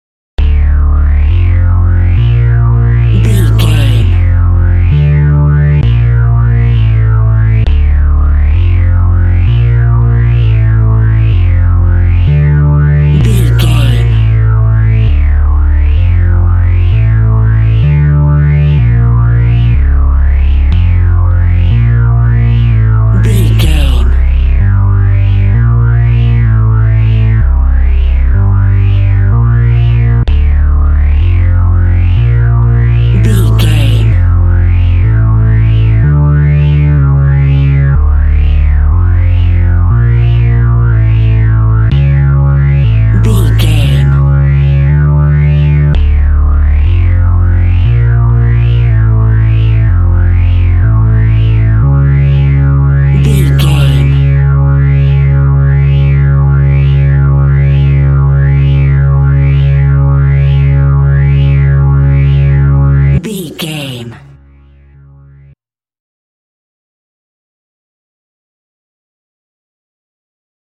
In-crescendo
Thriller
Aeolian/Minor
scary
tension
ominous
dark
suspense
eerie
Horror synth
Horror Ambience
electronics
synthesizer